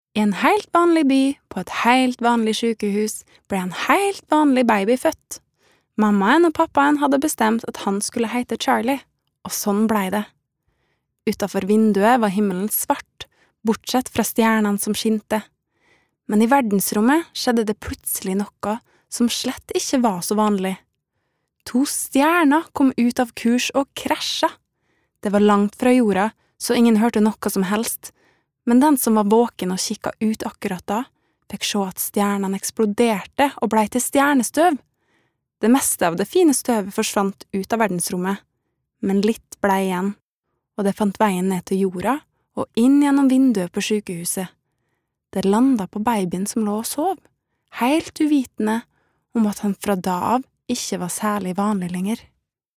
Romsdalsdialekt